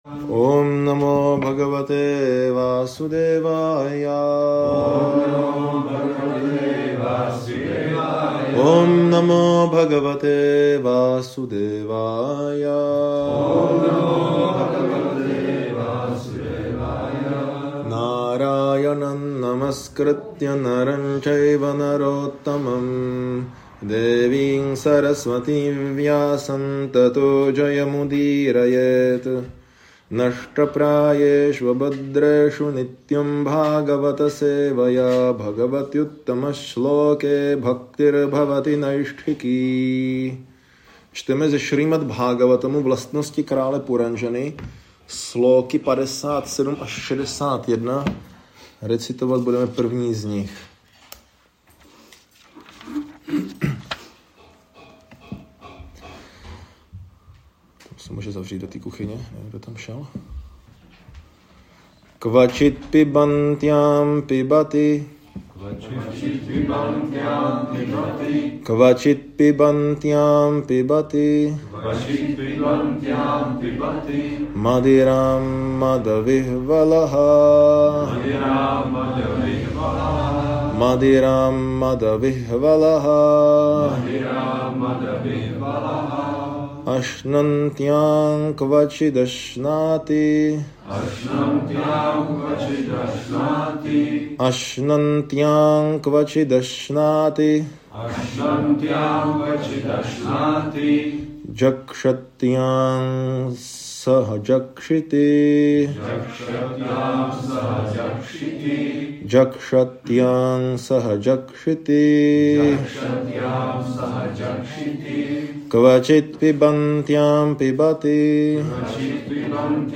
Jak hmotná inteligence ovládá duši a vede k otroctví | Přednášky | Audio | Prabhupád Bhavan
Brno - Prabhupád Bhavan